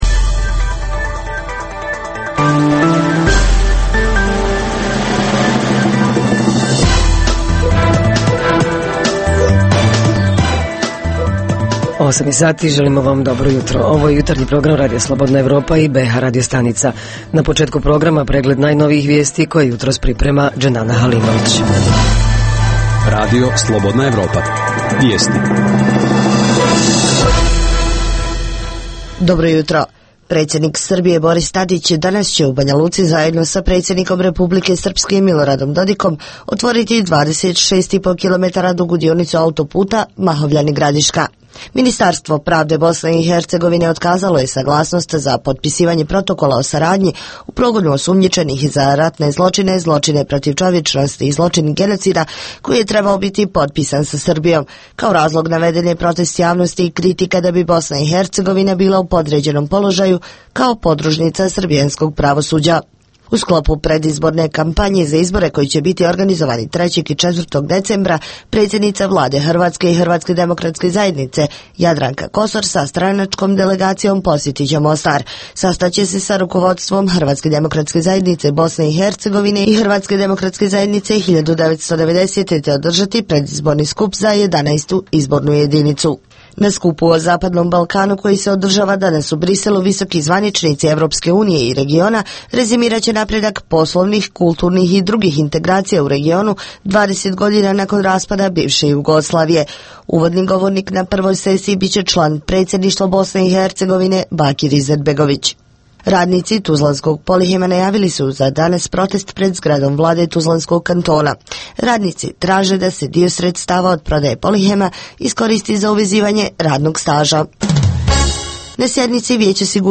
Ovog jutra govorimo o raznim pozitivnim primjerima iz naših sredina. Reporteri iz cijele BiH javljaju o najaktuelnijim događajima u njihovim sredinama.